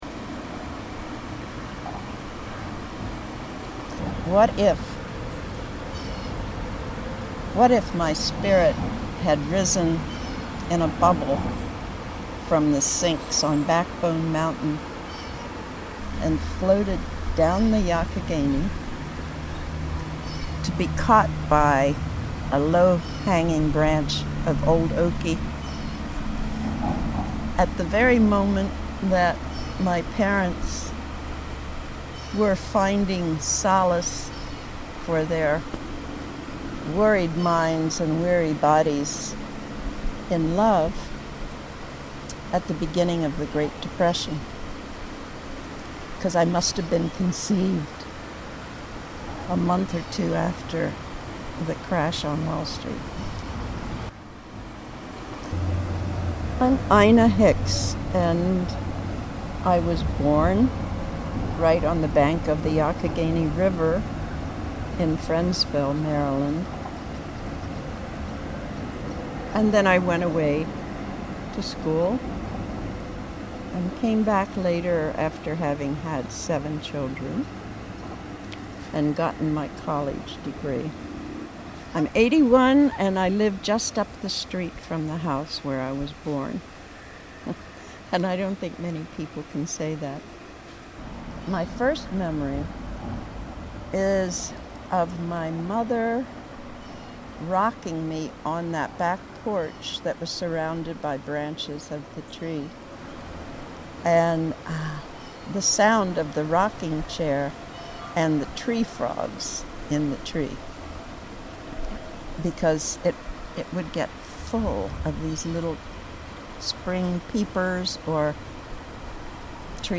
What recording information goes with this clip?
Tree stories is a collection of audio stories that were recorded in Allegheny and Garrett counties over a summer and fall in Western Maryland.